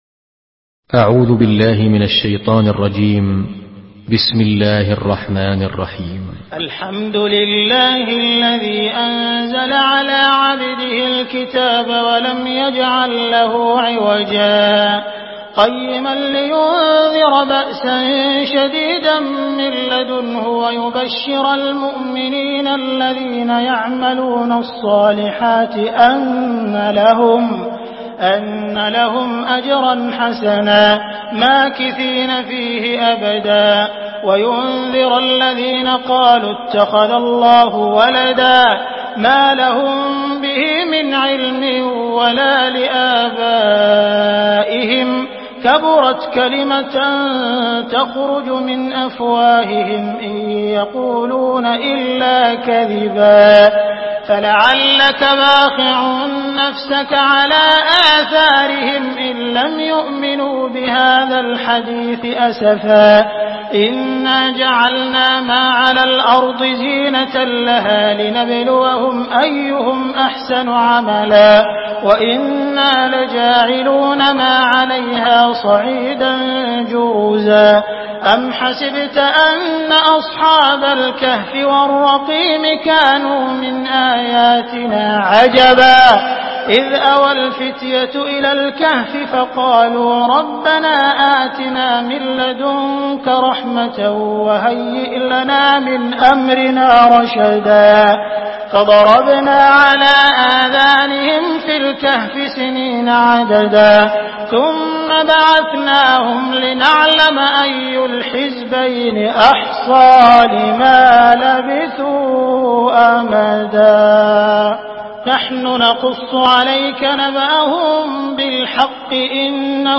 Surah আল-কাহফ MP3 by Abdul Rahman Al Sudais in Hafs An Asim narration.
Murattal